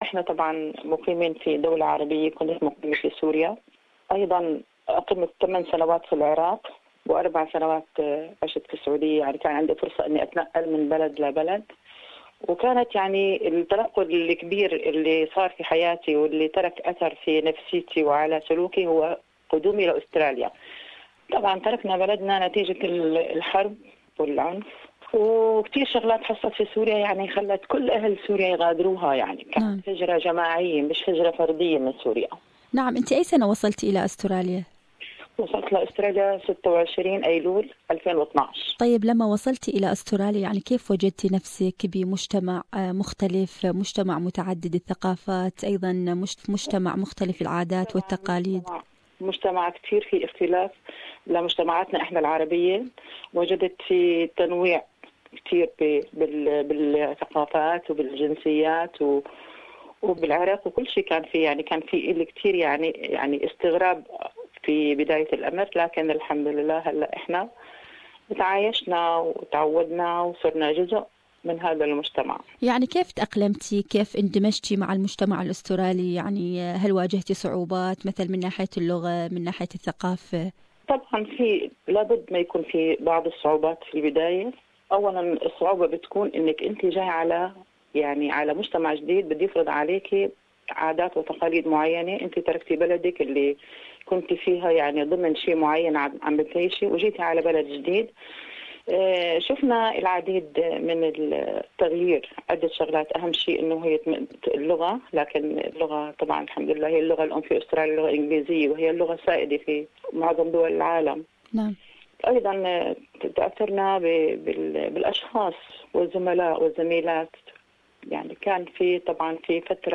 Group of Women